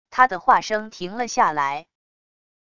他的话声停了下来wav音频生成系统WAV Audio Player